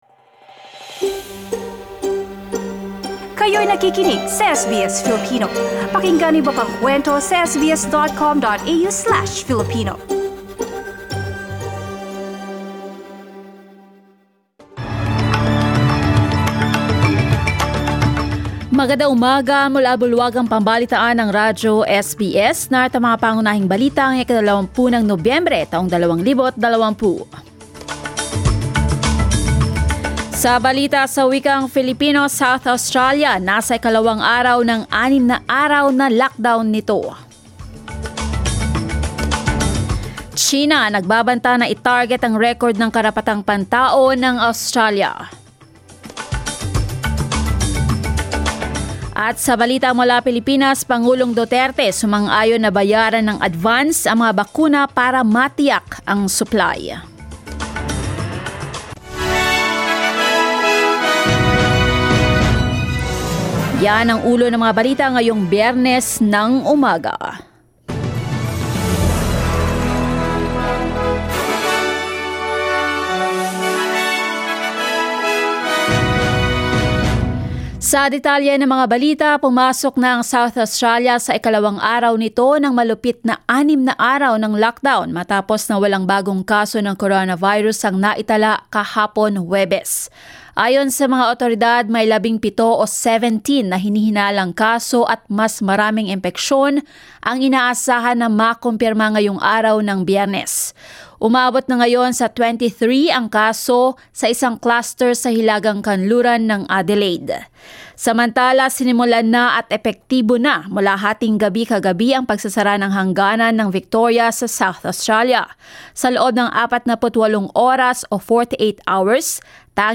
SBS News in Filipino, Friday 20 November